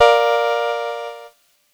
Cheese Chord 04-A#2.wav